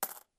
coin_coin_12.ogg